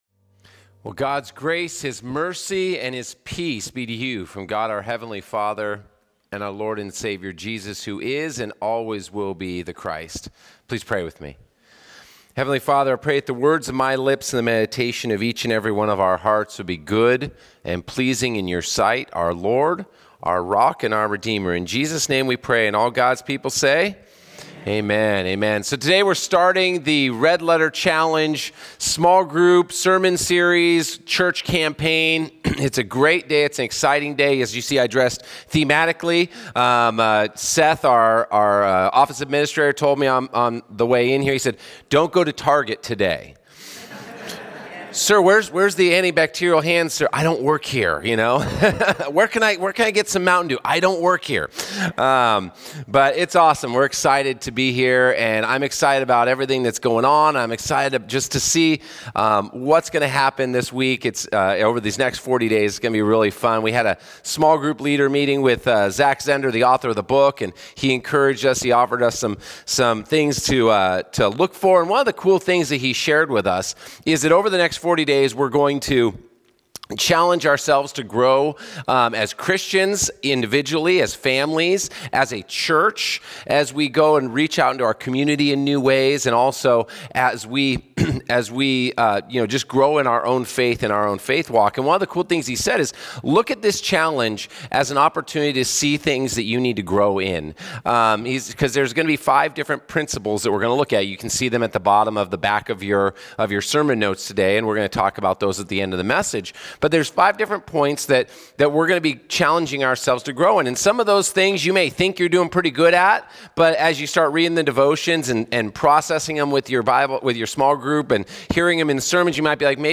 129-Sermon.mp3